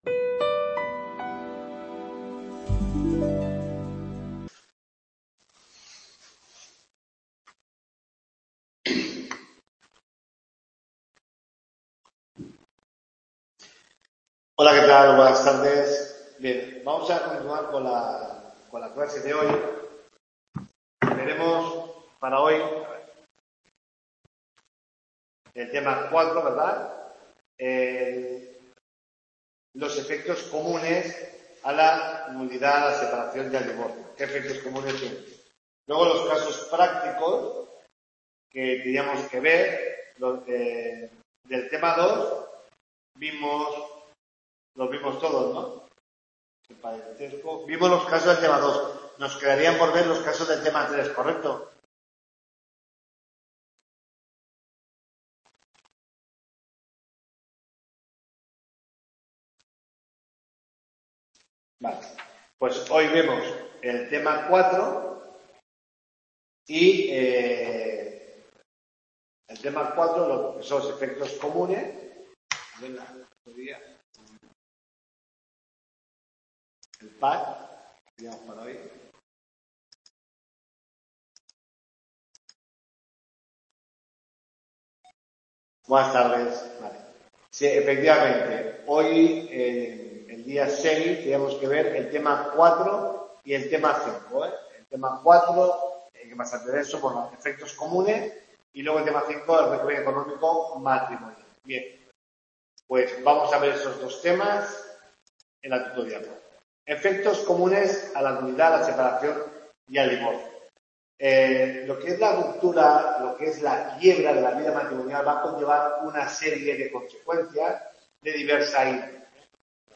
TUTORIA 4